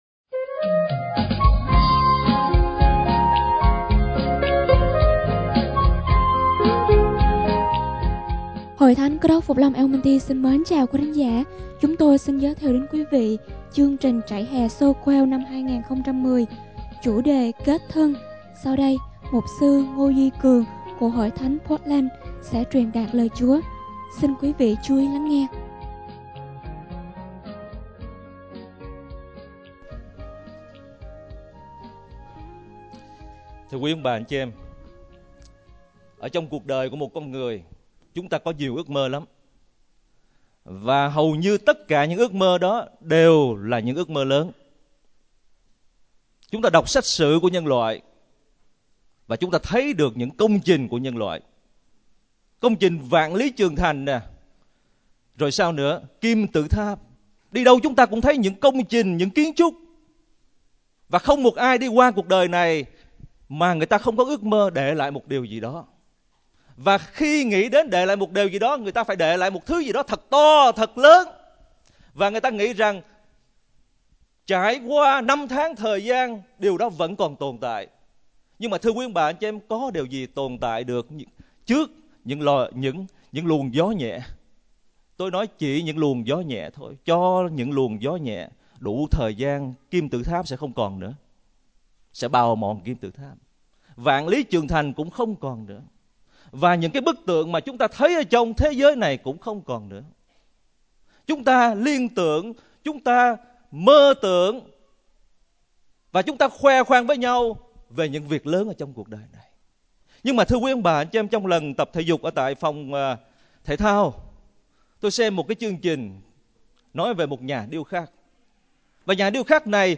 Trại Hè Soquel 2010
Sermon / Bài Giảng